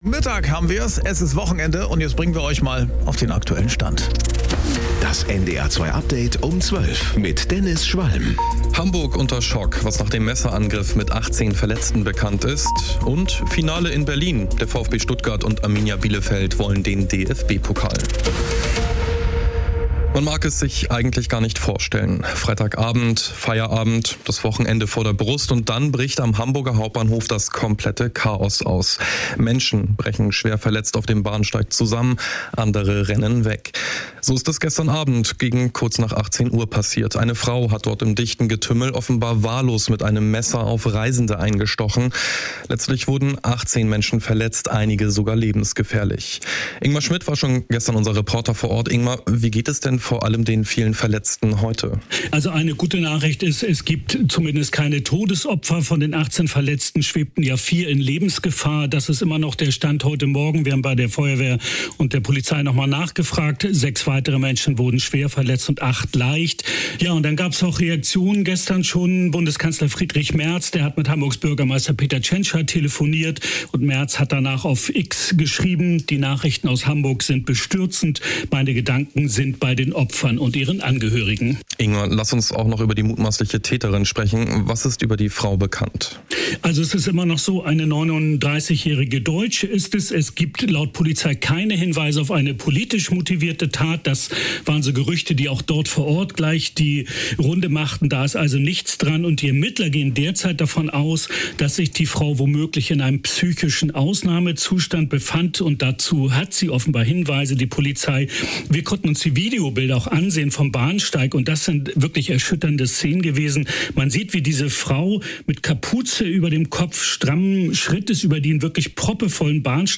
Mit unseren Korrespondent*innen und Reporter*innen, im Norden, in Deutschland und in der Welt.
… continue reading 228 эпизодов # Kurier Um 12 # NDR 2 # Tägliche Nachrichten # Nachrichten